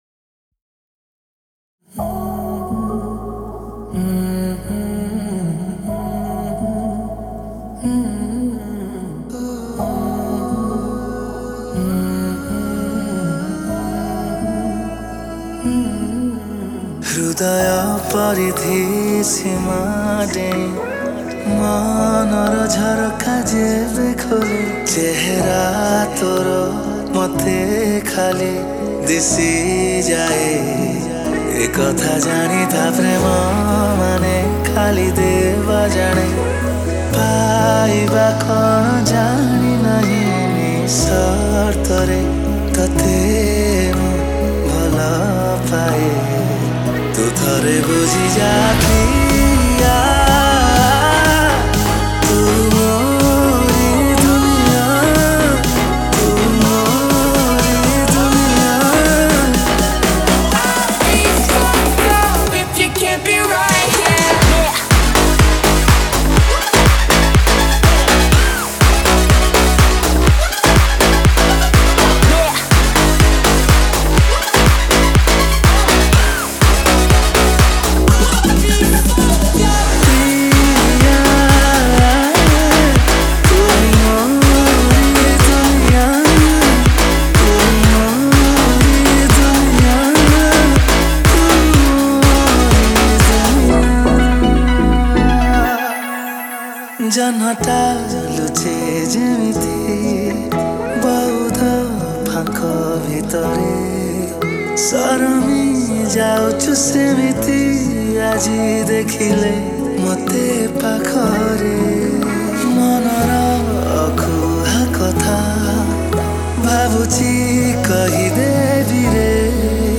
Romantic Love Dj Remix Songs Download